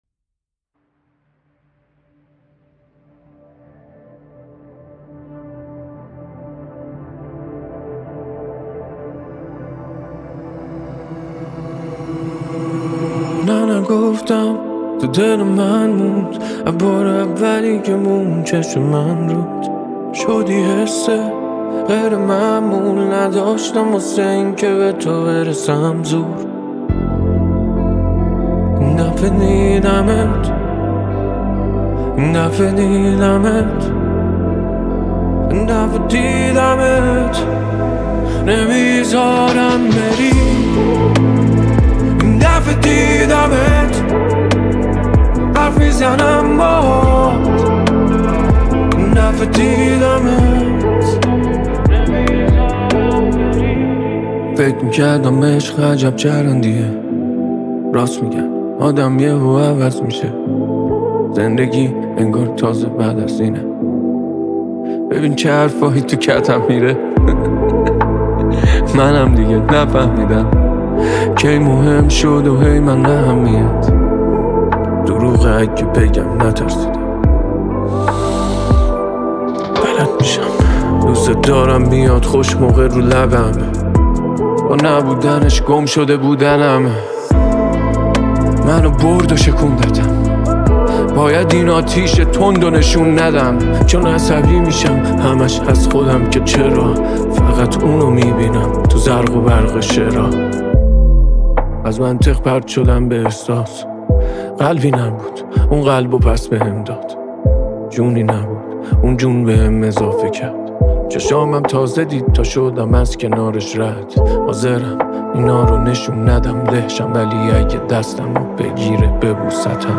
رپ فارسی